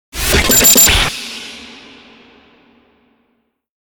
FX-033-IMPACT COMBO
FX-033-IMPACT-COMBO.mp3